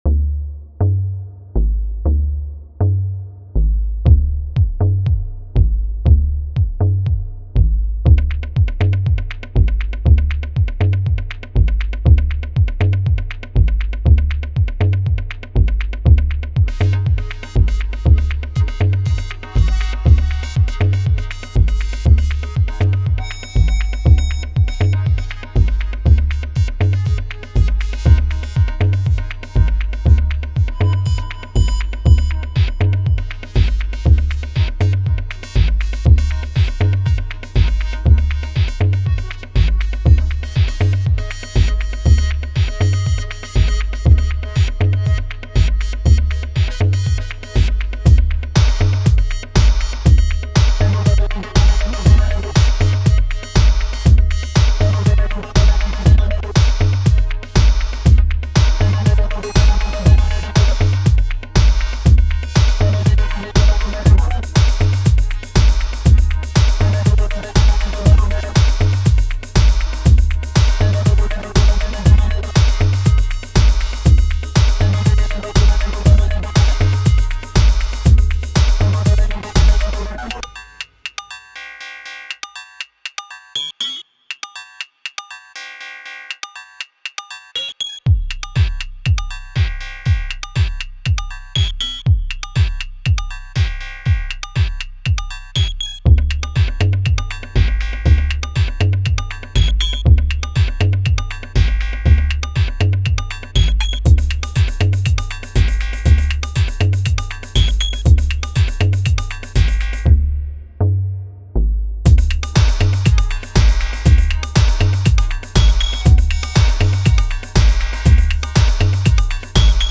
Blocks, Patterns, Pulses repeated